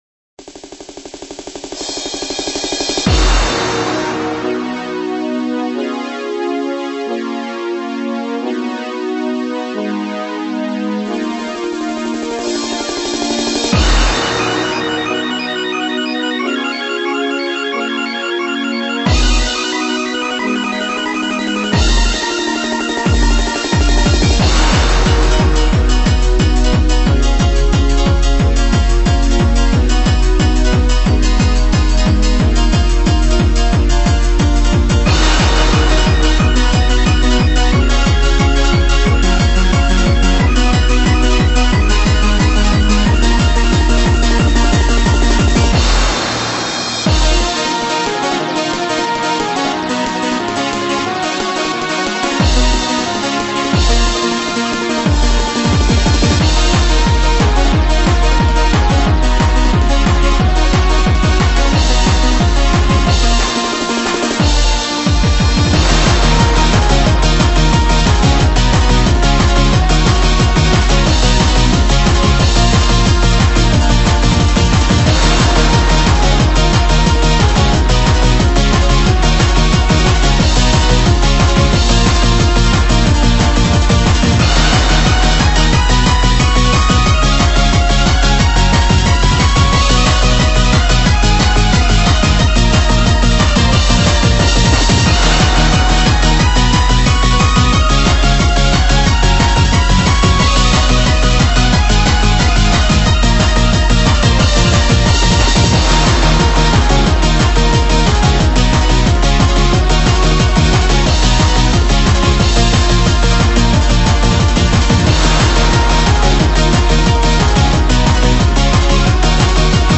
内容は、制作当初ハピコアのつもりだったんだけど、スーパーユーロになってきちゃったトカ（汗）。